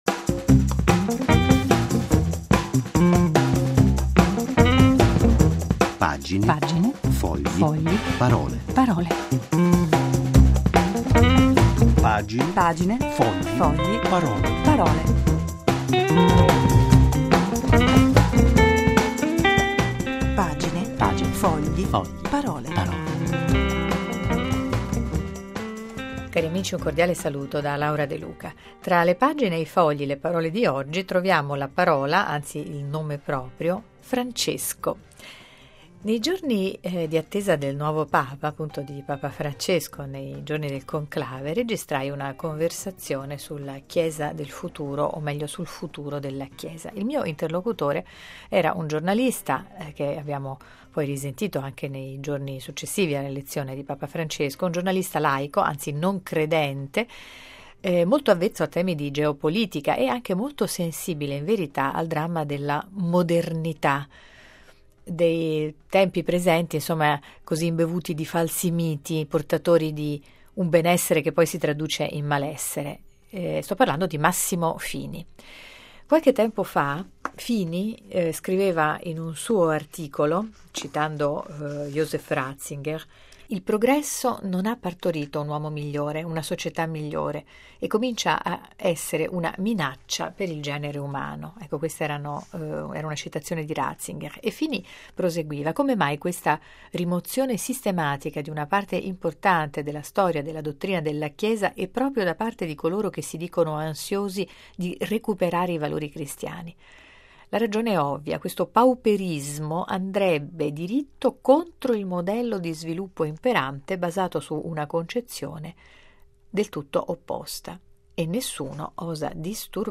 In occasione dell’elezione di papa Francesco alcune riflessioni fra chiesa e tempo presente con il giornalista Massimo Fini, che, da laico, reclama una guida più spirituale e meno mondana nella chiesa universale. Intervista